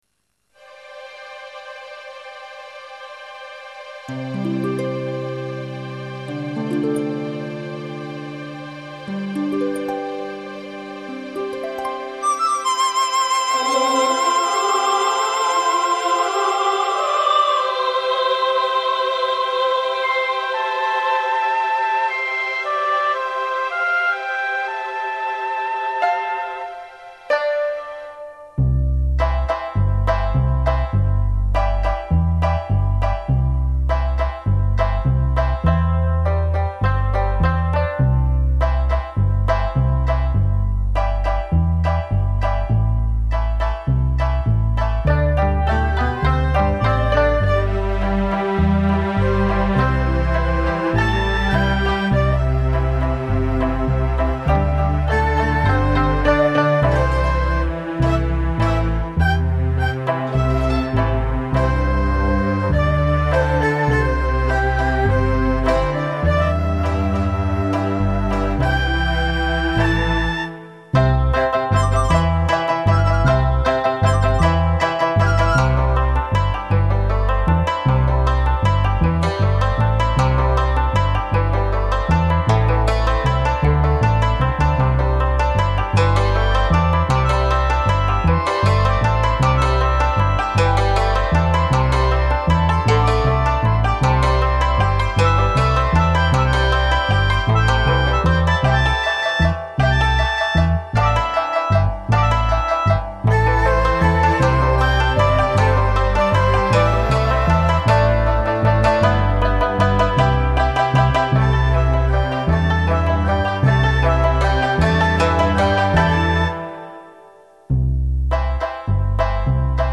无 调式 : C 曲类